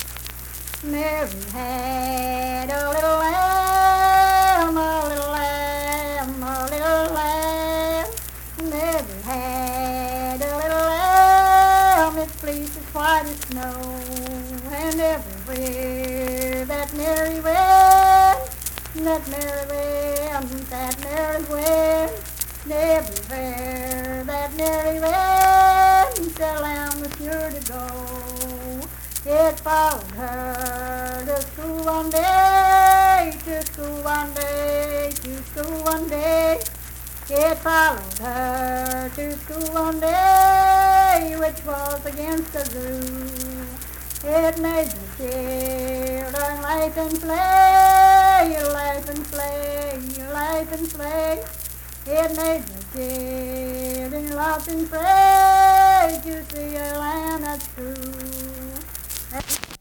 Unaccompanied vocal music performance
Verse-refrain 4(4w/R).
Children's Songs
Voice (sung)
Mingo County (W. Va.), Kirk (W. Va.)